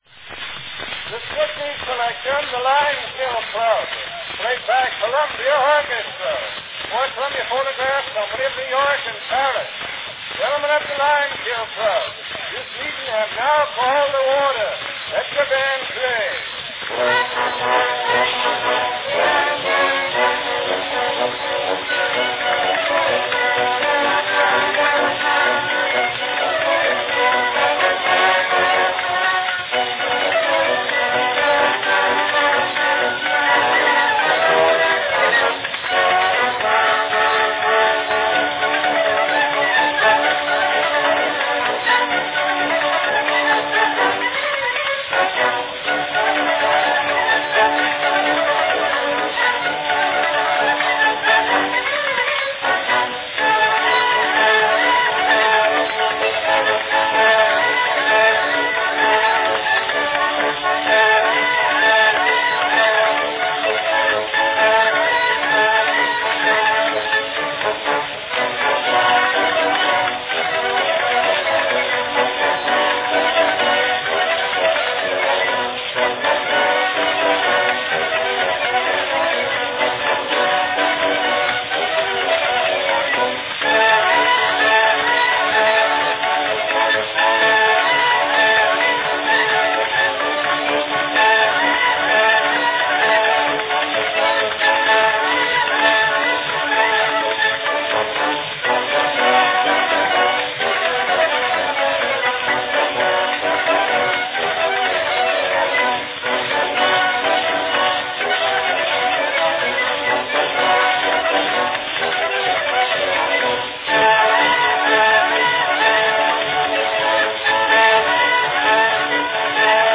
From 1899, a very catchy early cakewalk tune imitating the goings-on at a dance of The Lime-Kiln Club.
Cylinder # 15089 (5-inch "grand" cylinder)
Category Descriptive selection
Performed by Columbia Orchestra
This early recording of a cakewalk dance was intended to match the record-buying public's expectations of a typical energetic dance at the Club.